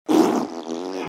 Farting Noises Sfx - Bouton d'effet sonore